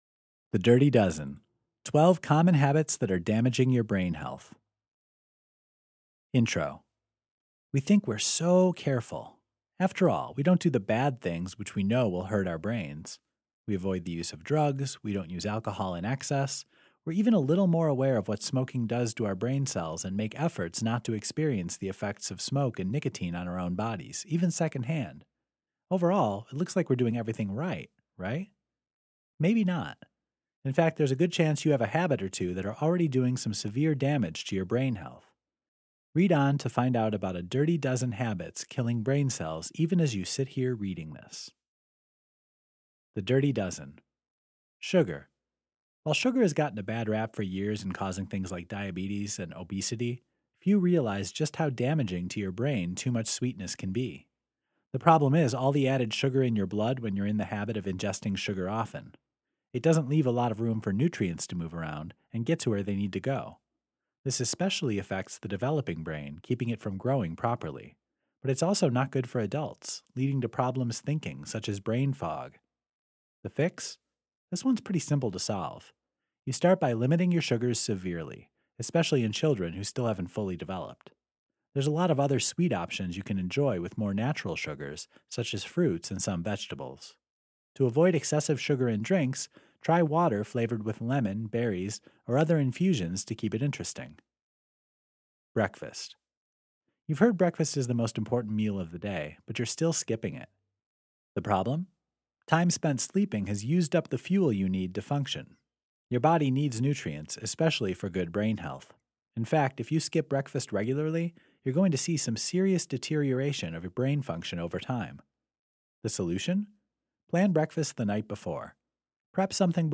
Bundle includes book, workbook, and audiobook!